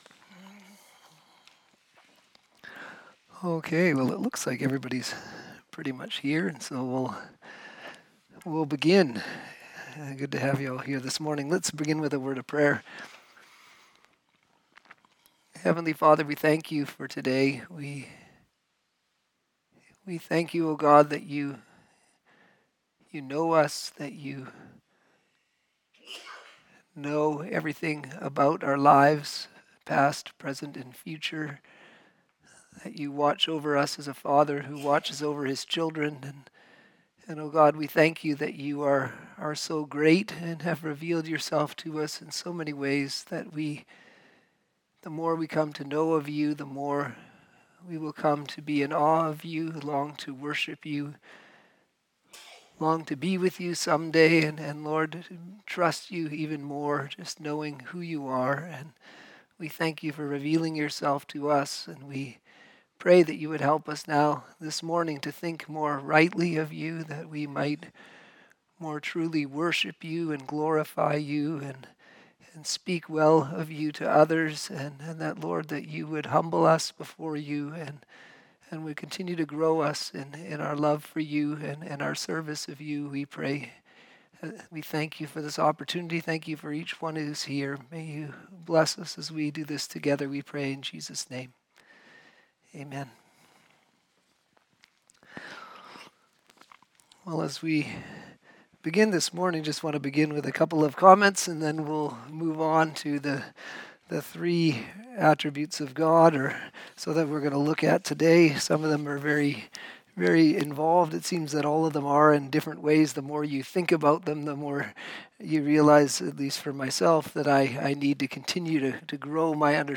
Sermons